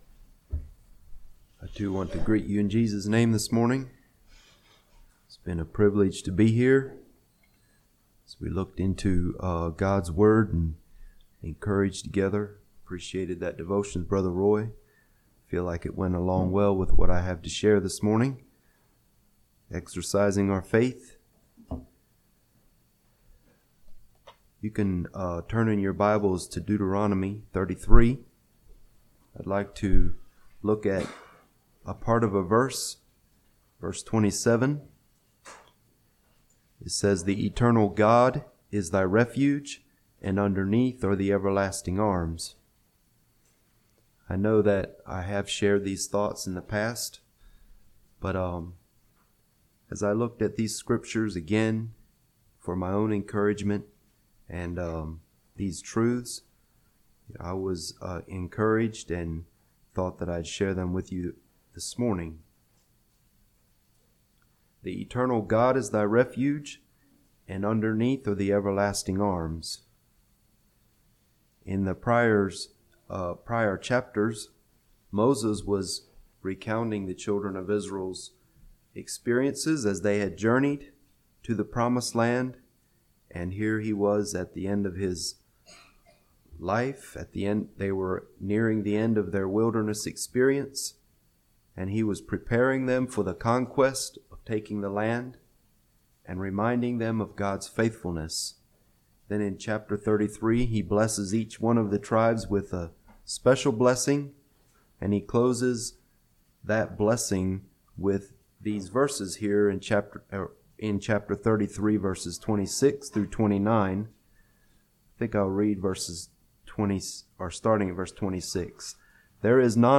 Play Now Download to Device The Eternal God Is Thy Refuge Congregation: Dublin Speaker